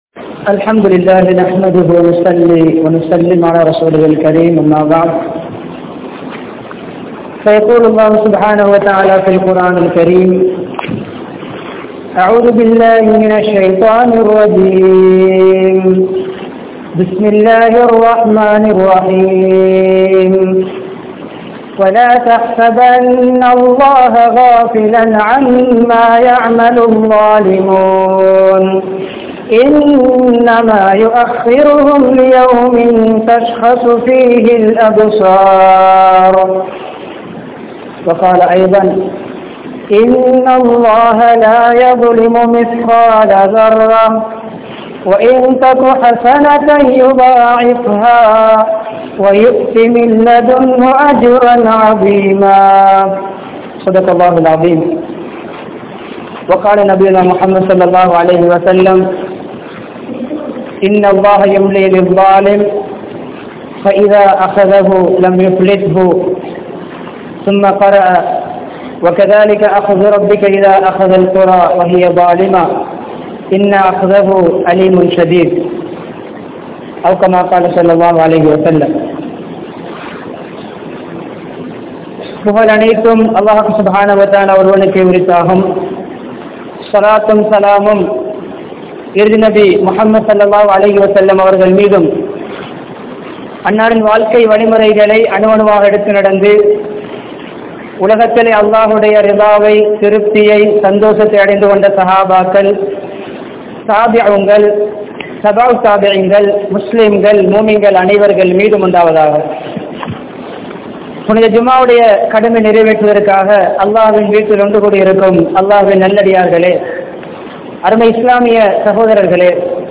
Aniyaayathin Vilaivuhal (அநியாயத்தின் விளைவுகள்) | Audio Bayans | All Ceylon Muslim Youth Community | Addalaichenai